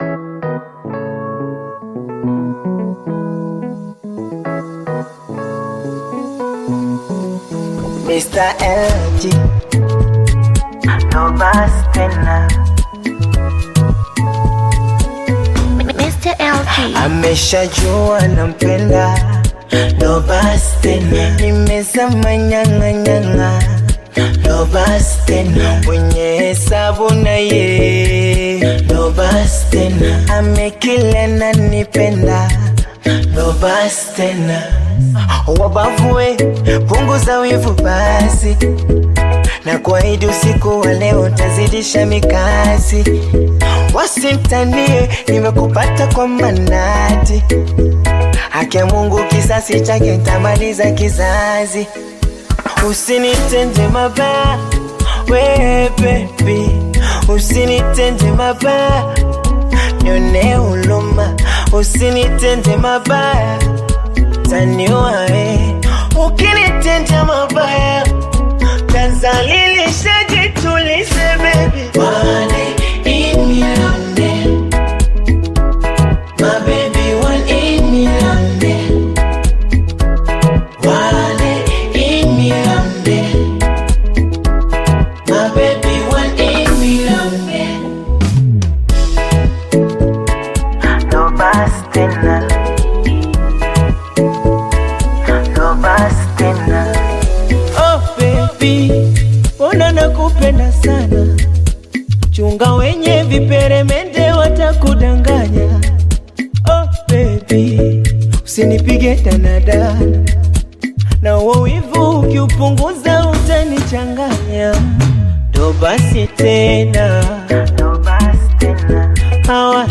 Bongo Flava music track
Tanzanian Bongo Flava artist, singer and songwriter